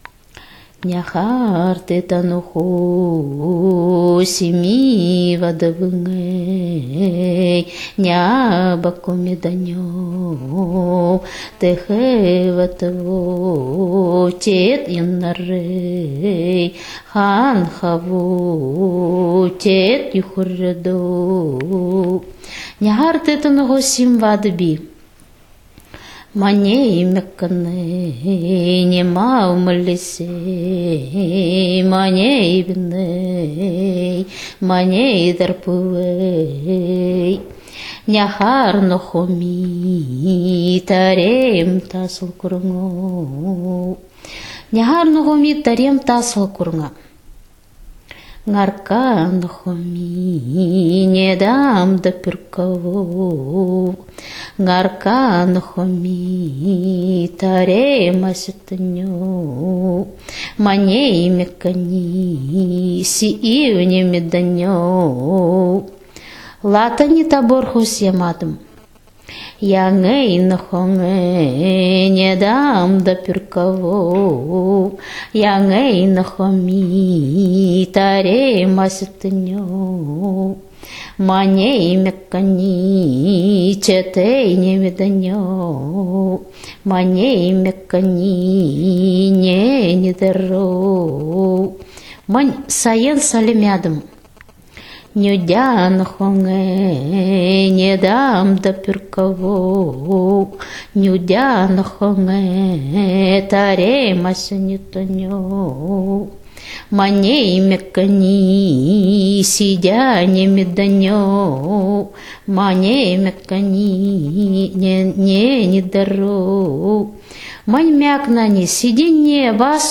Фрагмент эпической песни «Сэр” Я’ Ӈăцекы» («Ребёнок Белой Земли»). Эпическая песня исполняется на большеземельском (центральном) диалекте тундрового ненецкого языка.